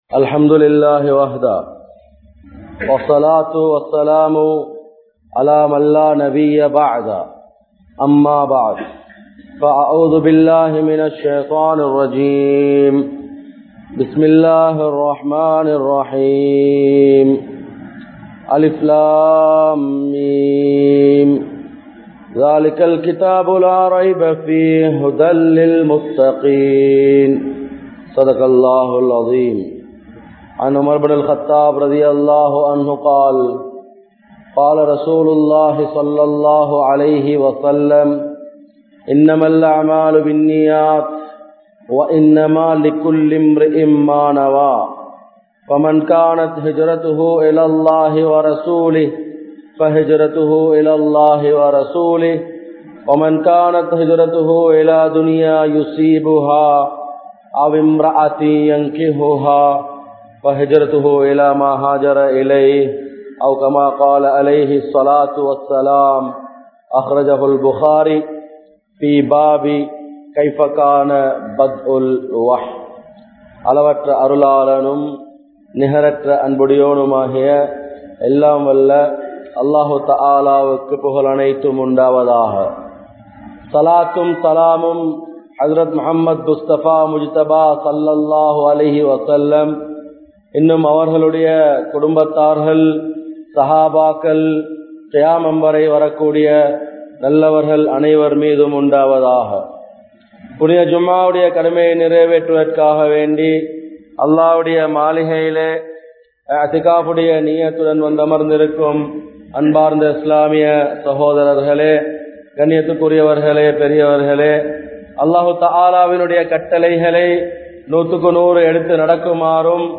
Islam Koorum Kodukkal Vaangal (இஸ்லாம் கூறும் கொடுக்கல் வாங்கல்) | Audio Bayans | All Ceylon Muslim Youth Community | Addalaichenai
Mallawapitiya Jumua Masjidh